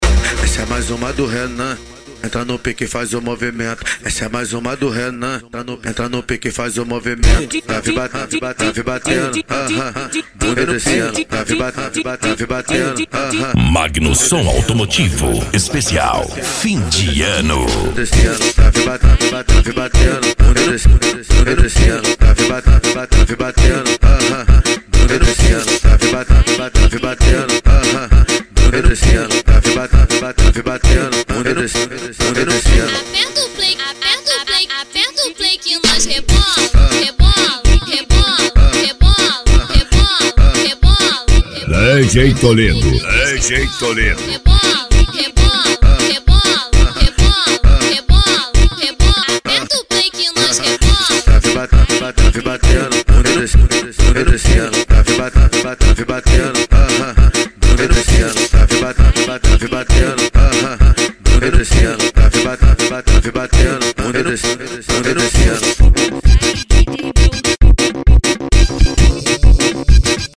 Funk
Sertanejo Universitario